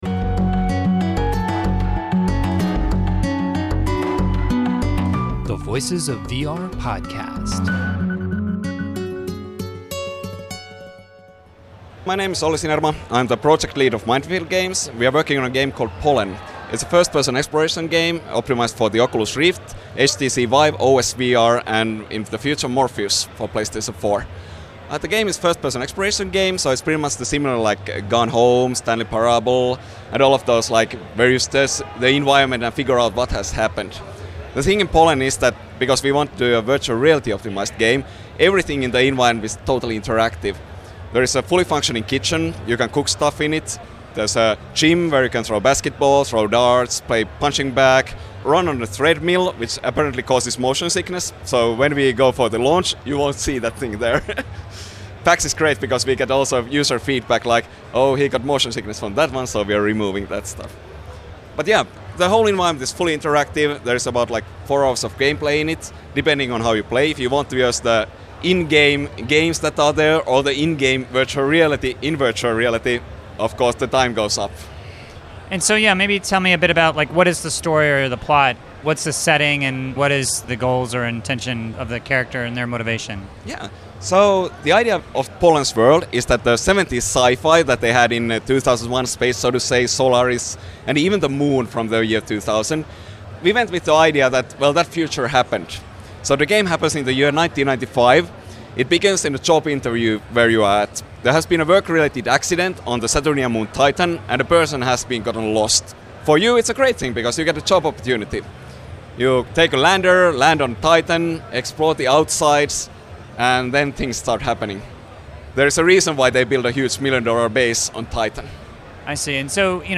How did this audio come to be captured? at PAX Prime